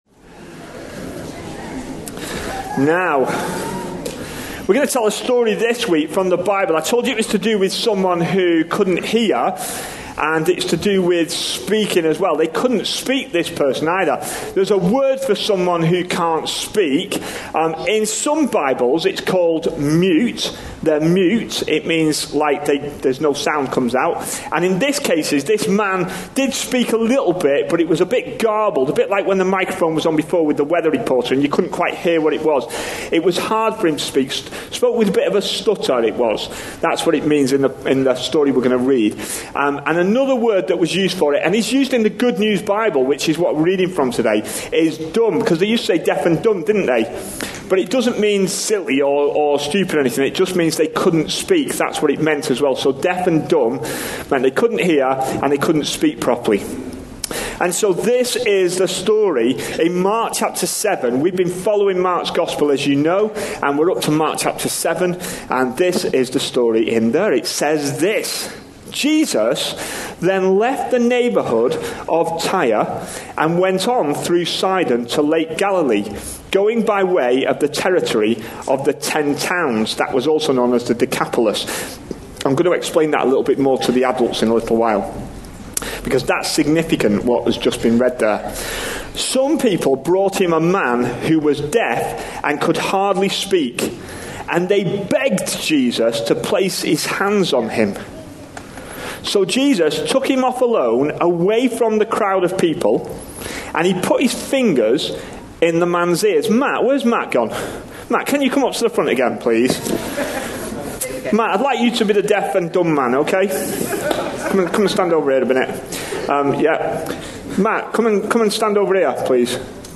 A sermon preached on 3rd March, 2019, as part of our Mark. series.
Mark 7:31-37 Listen online Details This all-age service talk was based on Mark 7:31-37 ("Jesus Heals a Deaf-Mute" from GNB) with a look back to the previous section, Mark 7:24-30 ("A Woman's Faith") and Isaiah 35:5-6, also references to Mark 8:18a & 16:15.